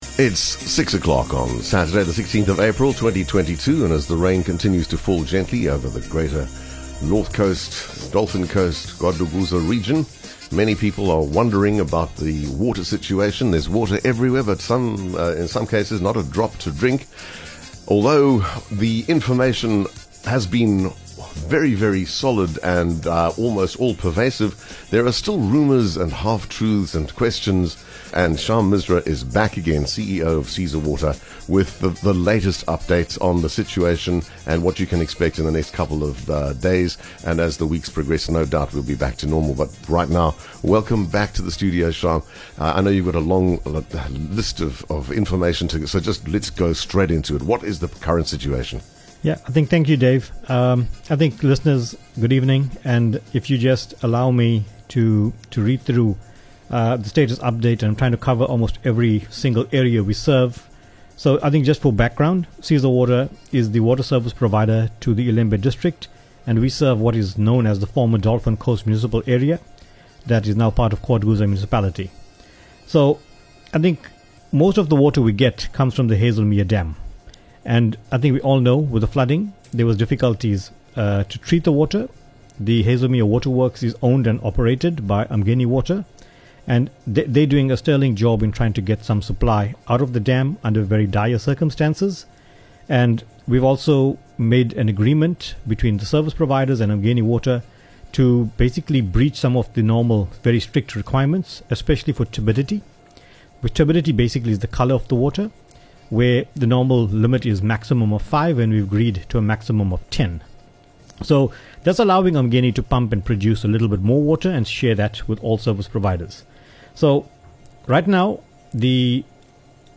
North Coast Water Crisis - Interview